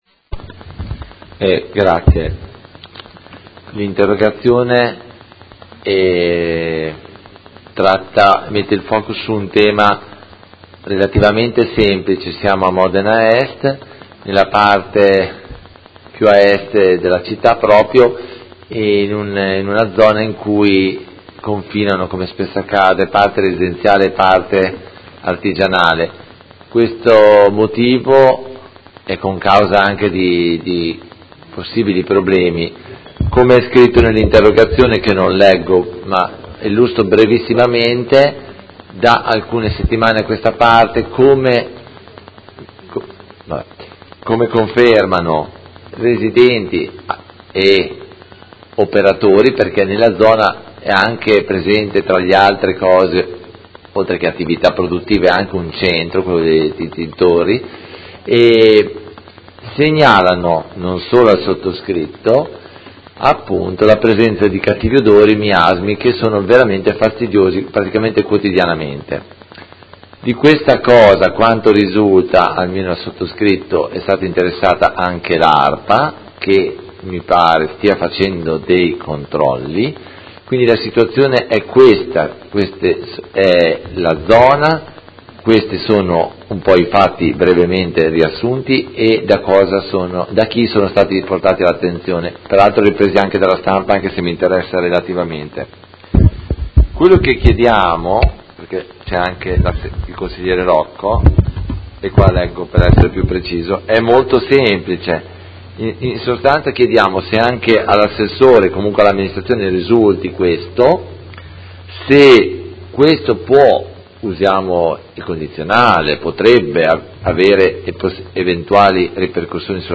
Seduta del 8/06/2017 Interrogazione dei Consiglieri Carpentieri (PD) e Rocco (Art.1-MDP) avente per oggetto: Miasmi e cattivi odori a Modena Est